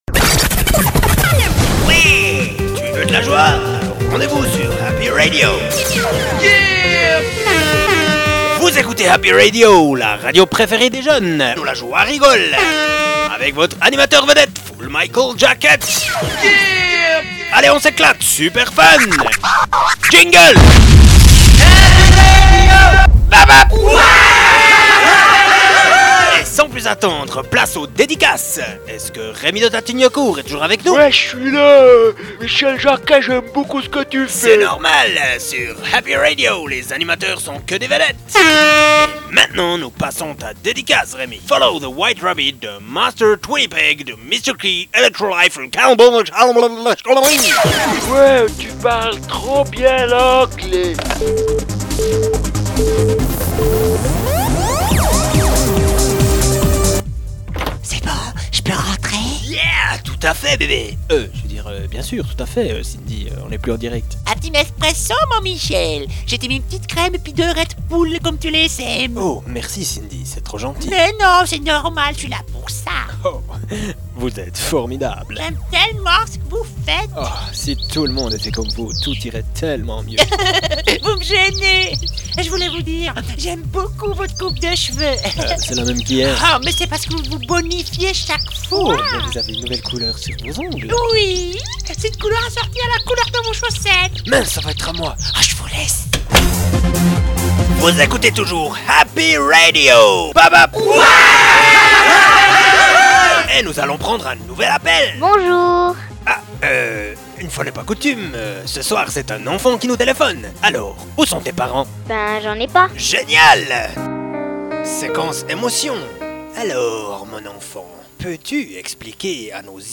Les sketches :